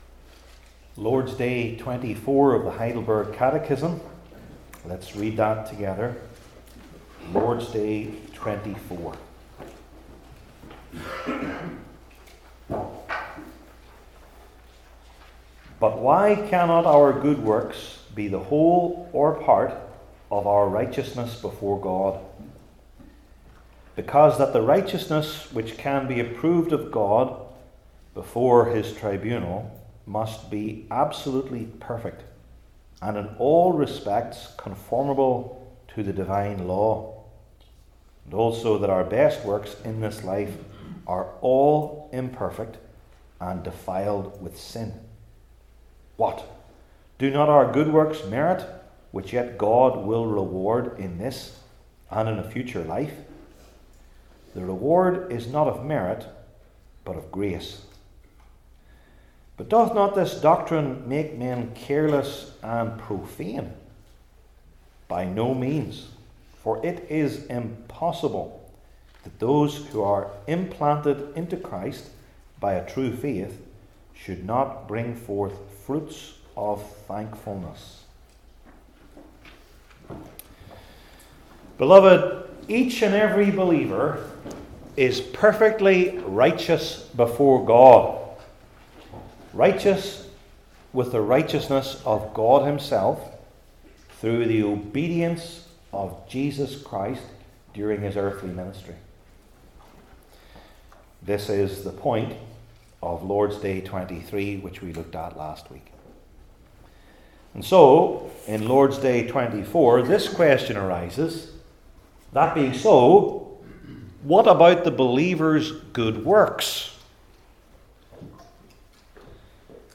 II Timothy 3 Service Type: Heidelberg Catechism Sermons I. Scripture Explains Good Works II.